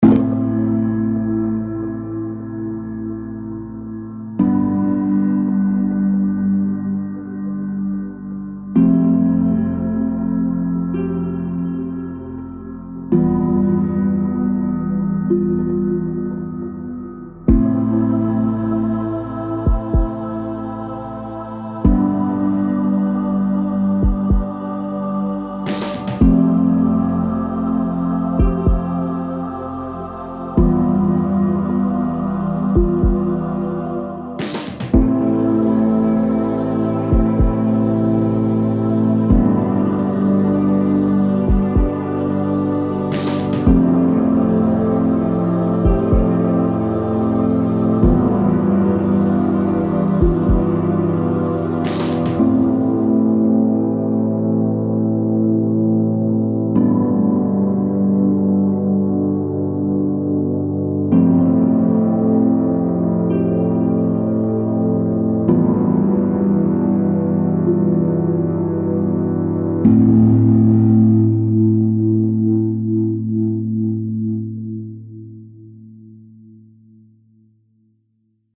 On a décidé de faire des musiques ambiantes qui pourraient coller avec un court métrage ou bien un film.